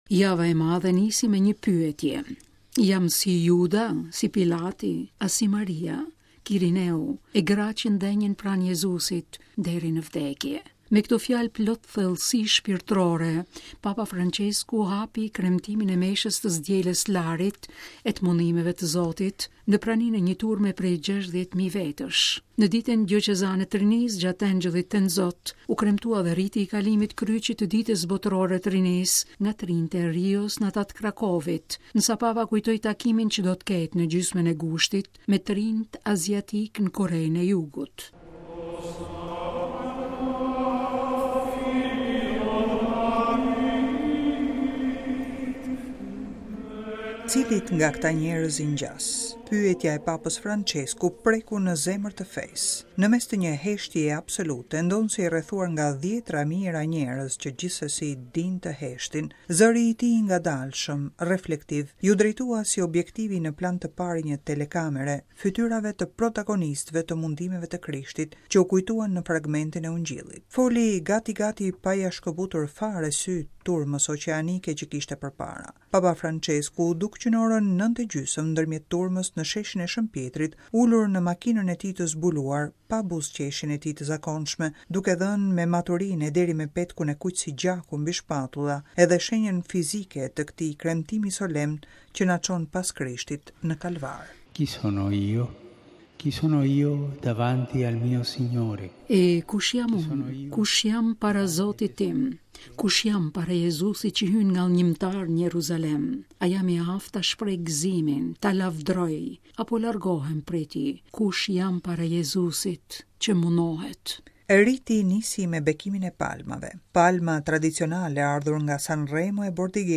Java e Madhe nisi me një pyetje: jam si Juda, si Pilati, a si Maria, Kirineu e gratë, që ndenjën pranë Jezusit deri në vdekje? Me këto fjalë, plot thellësi shpirtërore, Papa Françesku hapi kremtimin e Meshës të së Dielës së Larit e të Mundimeve të Zotit, në praninë e një turme prej 60 mijë vetësh.
Në mes të një heshtjeje absolute, ndonëse i rrethuar nga dhjetra mijëra njerëz, që gjithsesi dinë të heshtin, zëri i tij i ngadalshëm, reflektiv, iu drejtua, si objektivi në plan të parë i një telekamere, fytyrave të protagonistëve të Mundimeve të Krishtit, që u kujtuan në fragmentin e Ungjillit.